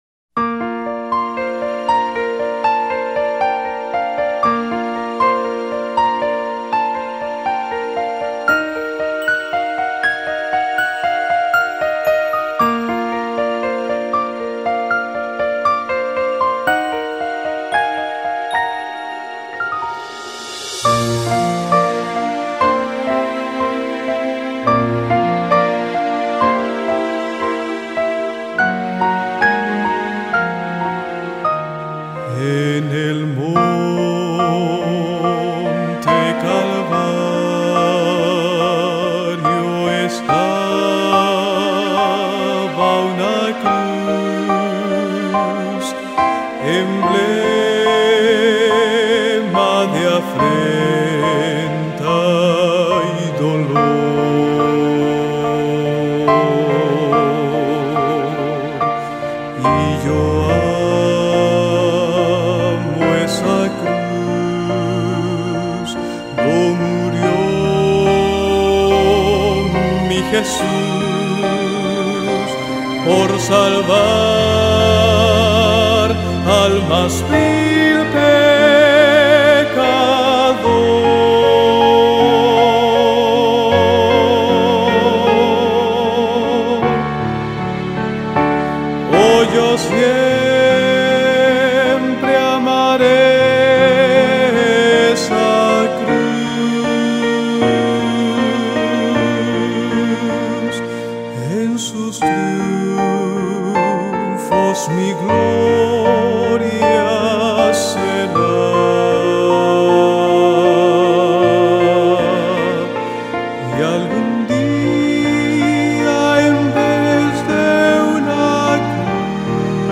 325_En_el_monte_Calvario_melodia_palabras.mp3